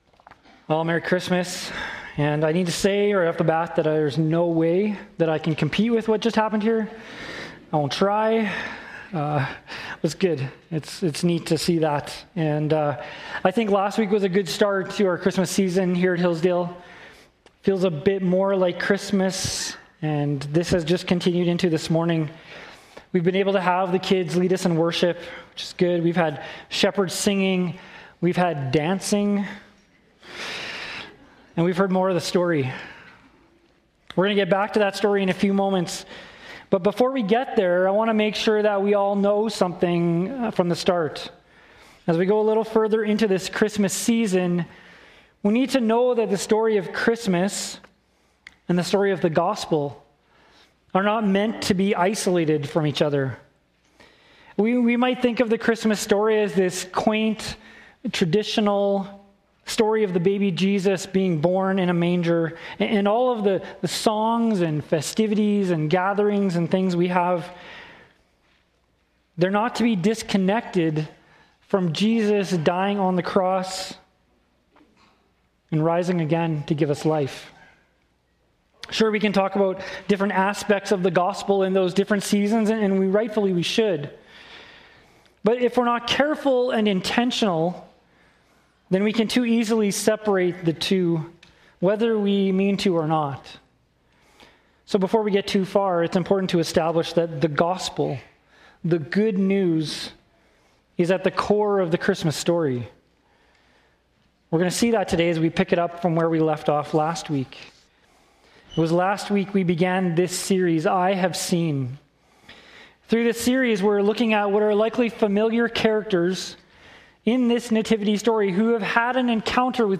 Audio Archive of Previous Sermons
Hillsdale Baptist Church Sermon Audio